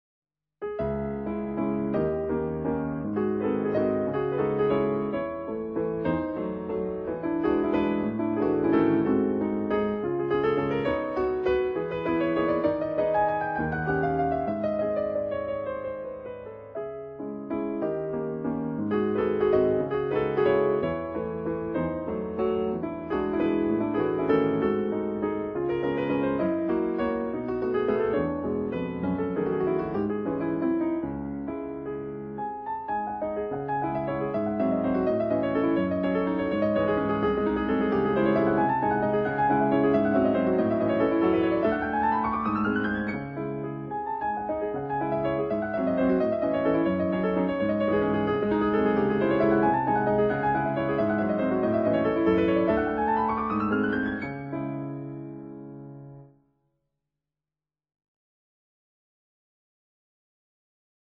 classical piano album